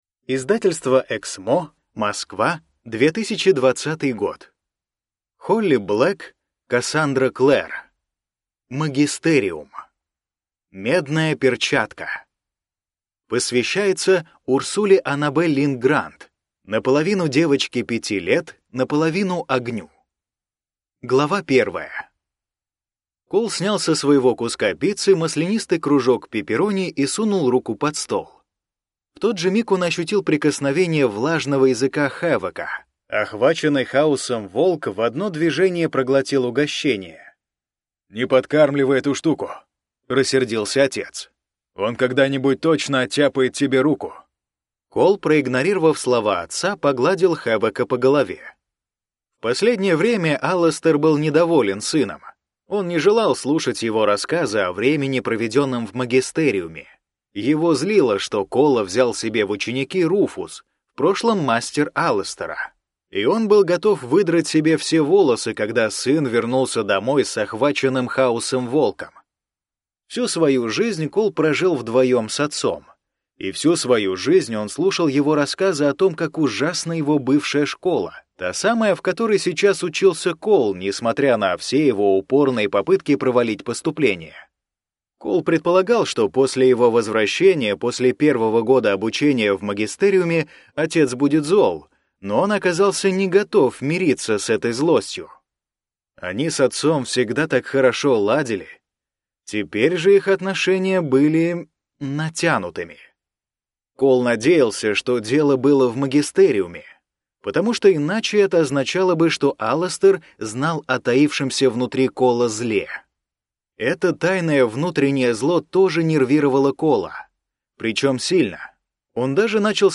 Аудиокнига Медная перчатка | Библиотека аудиокниг